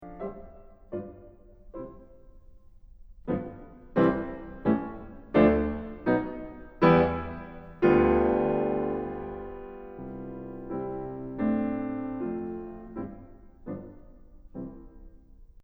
Back to the theme of the second movement: it is not a military march, but it has the influence of it.
It is typical of Beethoven: take something of a certain character ( a rigid march) and transform it to almost the opposite in character ( witty, surprising and with a soft touch).